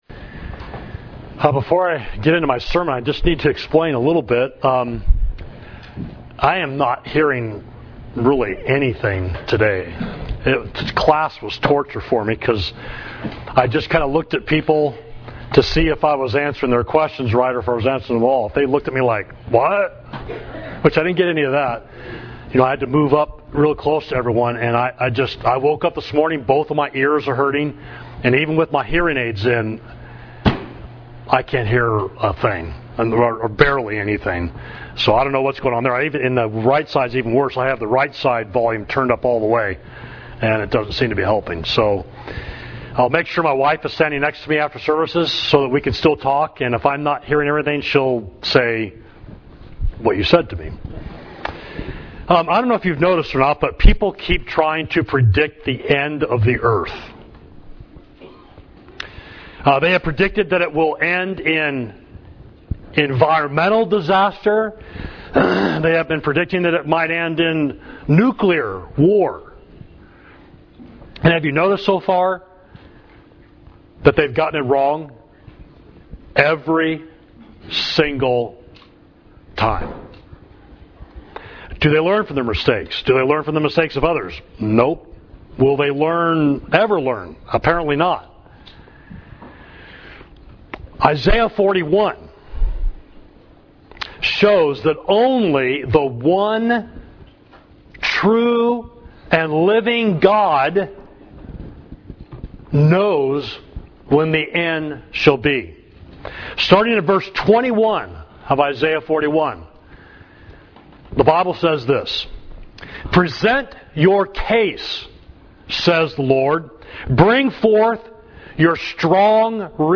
Sermon: When Will Jesus Return? Isaiah 41.21–24